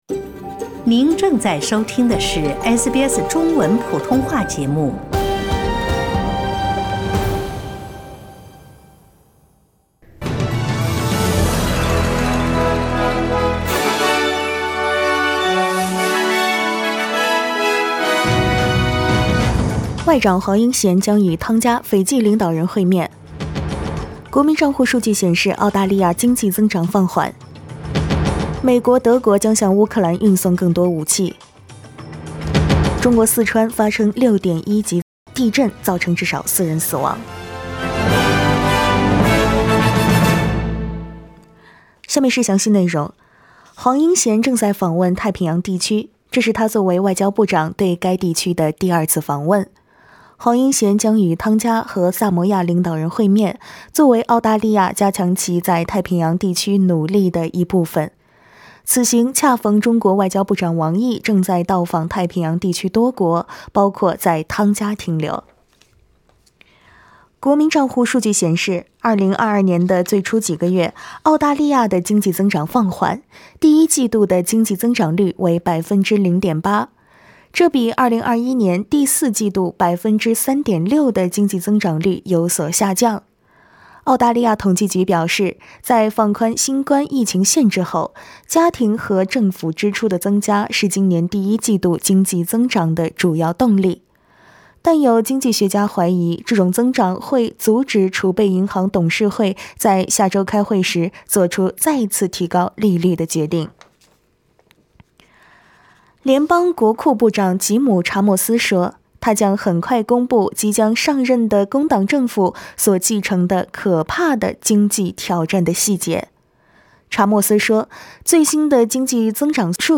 SBS早新闻（6月2日）